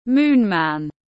Moon Man /ˈmuːn mæn/